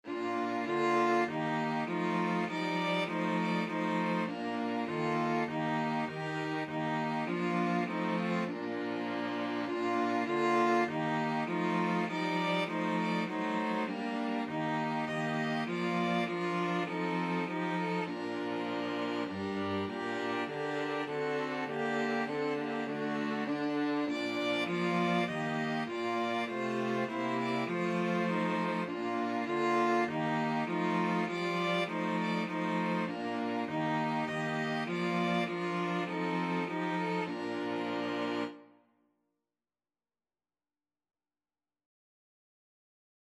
Christian
Violin 1Violin 2ViolaCello
4/4 (View more 4/4 Music)
Classical (View more Classical String Quartet Music)